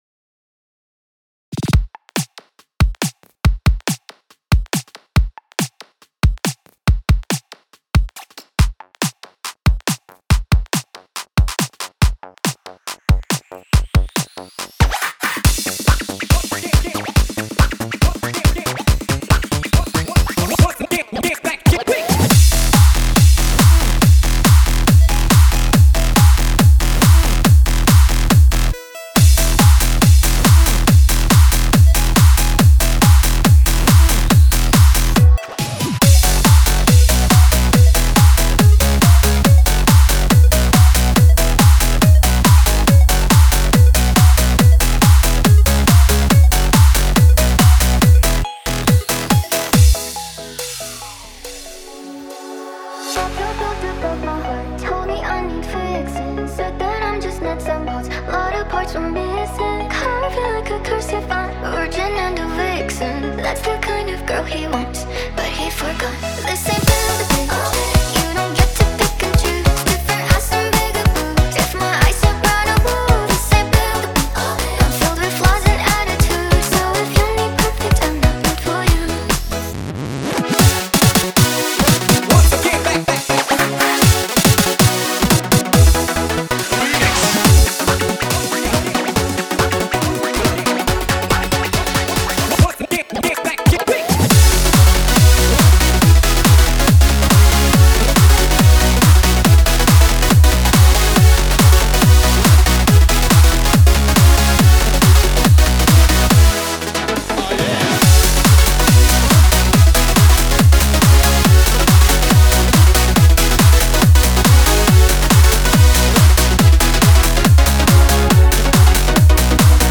New Hands Up remix up and pumping!